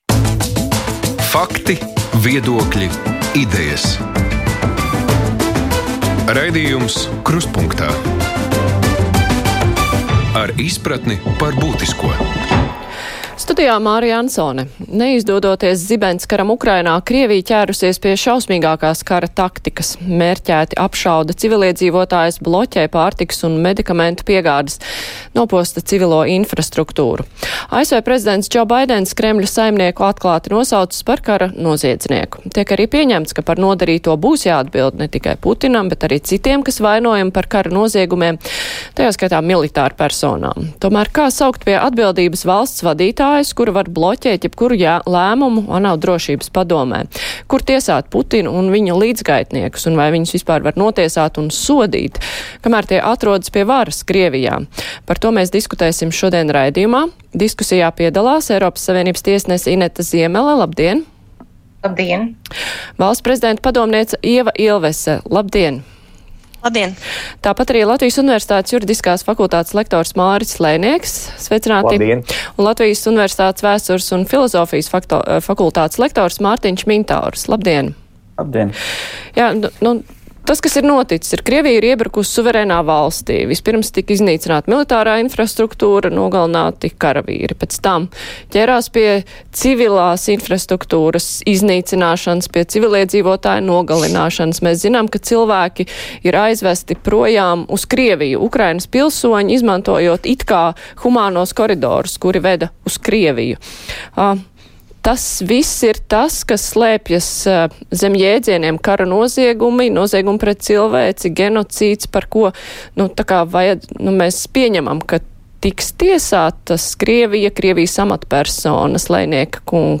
Krustpunktā diskusija: Kara noziegumi Ukrainā un iespējas sodīt Krievijas amatpersonas